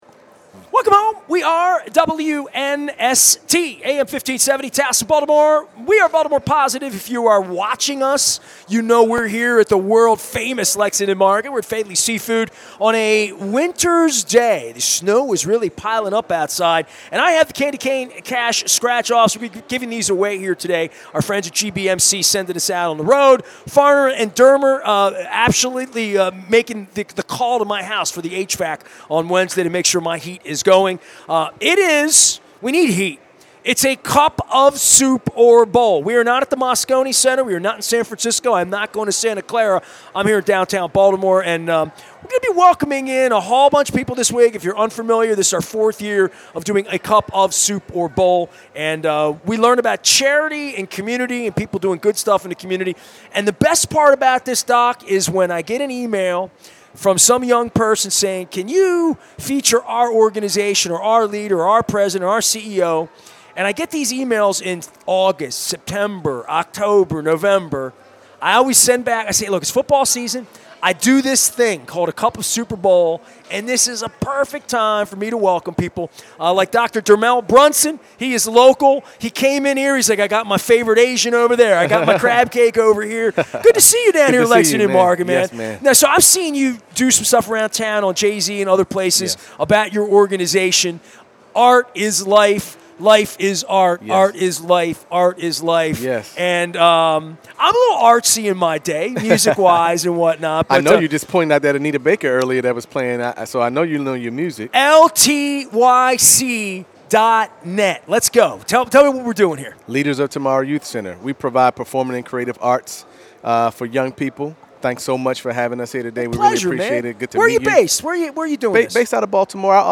at Faidley's Seafood in Lexington Market